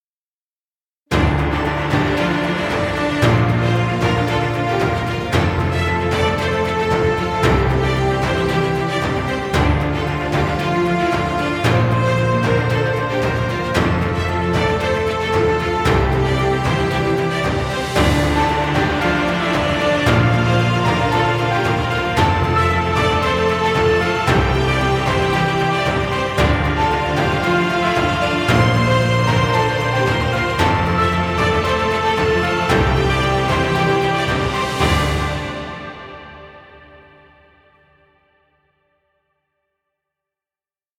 Inspirational epic cinematic music.
Stock Music. Background Music.